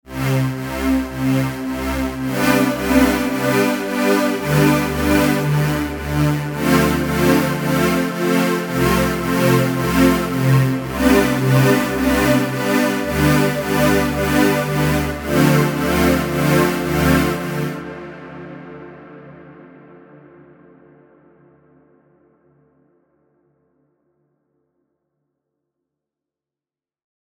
Dieser semi-modulare Synthesizer wird besonders für seine eher rauen bis dreckigen Sounds und seine Flexibilität geschätzt.
Klangbeispiel Preset „Cold Breeze“